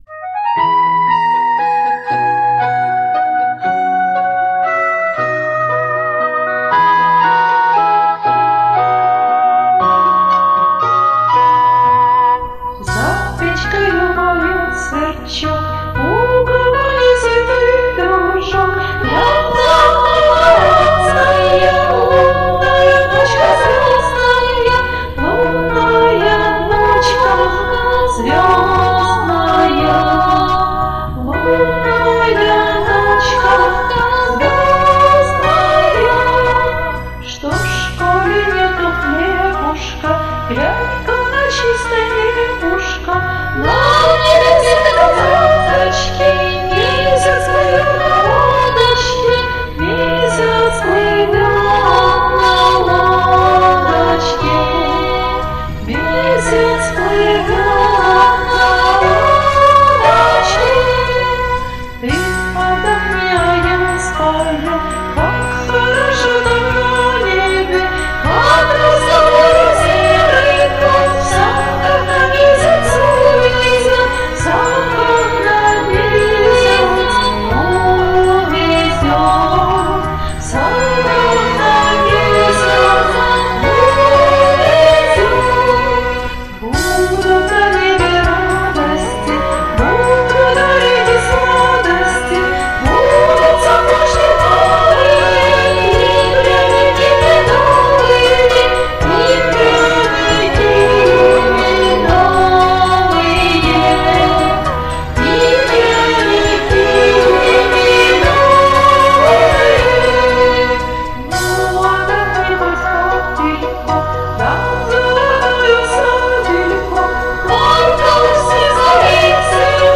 Так ласково многоголосье звучит - капелью.